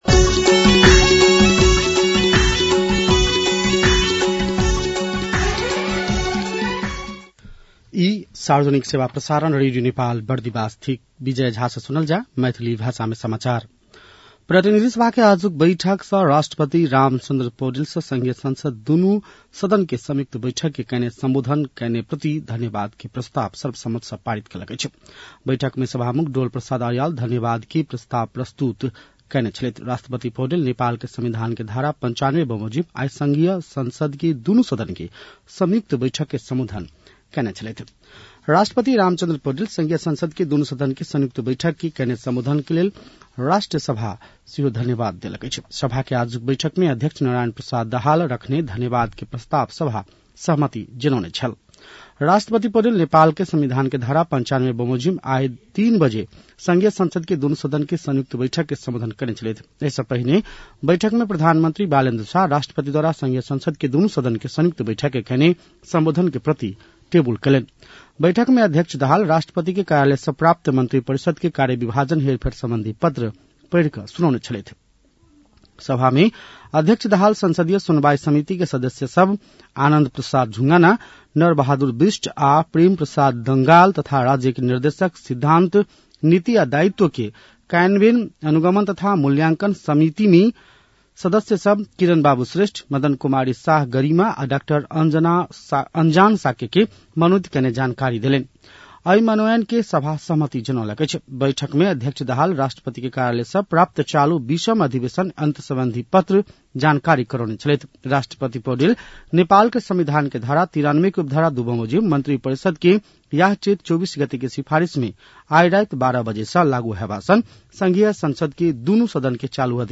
मैथिली भाषामा समाचार : २७ चैत , २०८२